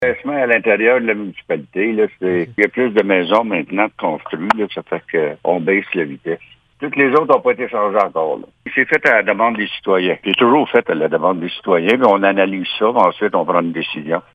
La vitesse est donc passée de 50 à 40 km/h à la demande de résidents. Le maire de Messines, Ronald Cross, en dit davantage :